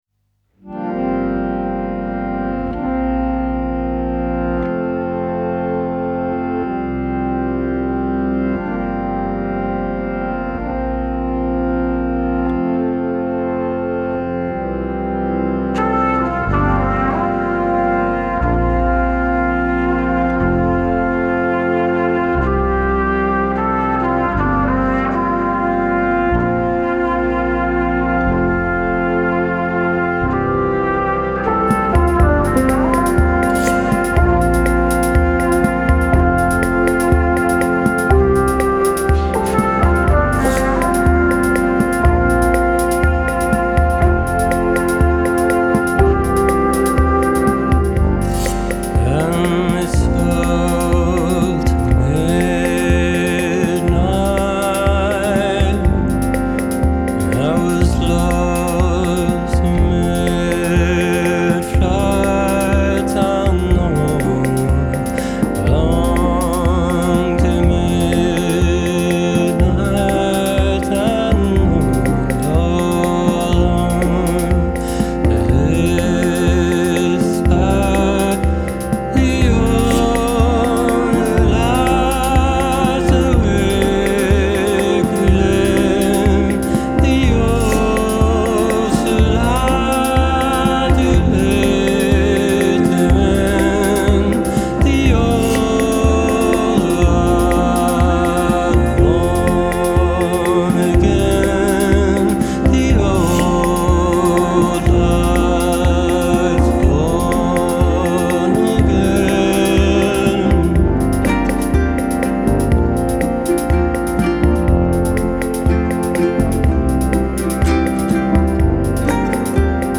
Genre : Alternatif et Indé